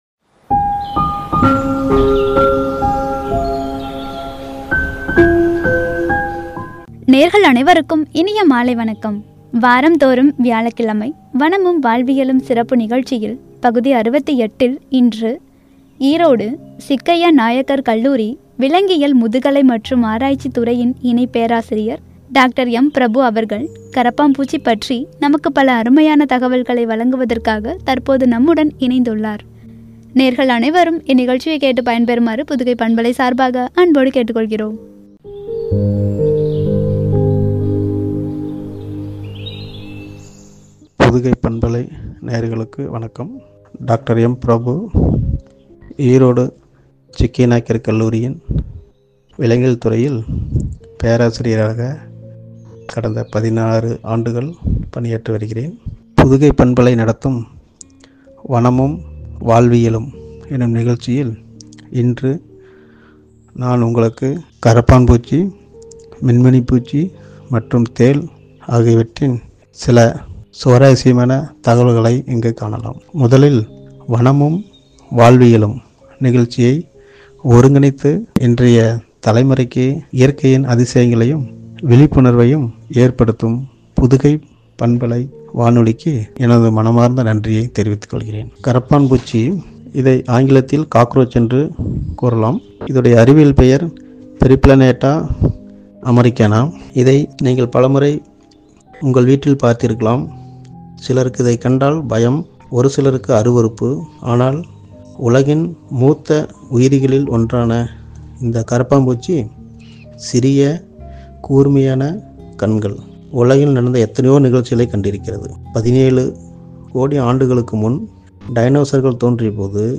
(வனமும், வாழ்வியலும்- பகுதி 68) “கரப்பான் பூச்சி” என்ற தலைப்பில் வழங்கிய உரை.